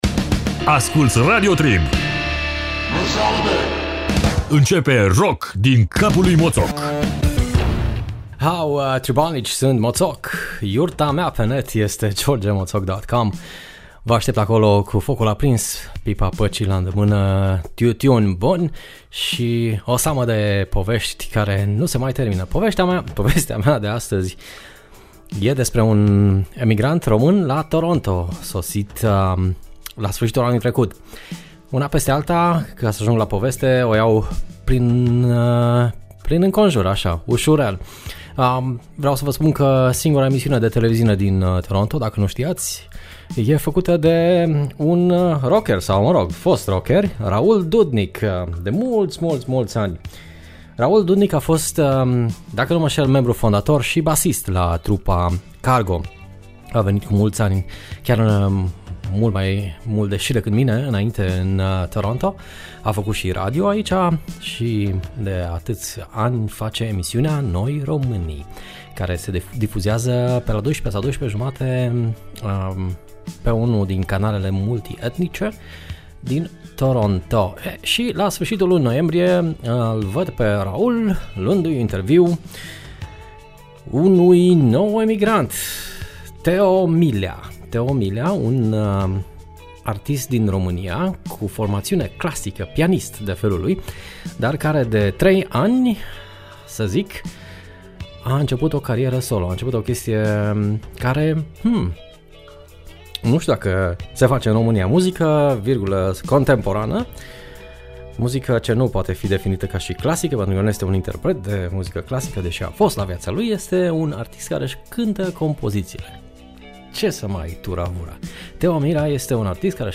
in emisiunea de azi am folosit un clip audio din emisiunea TV Noi Romanii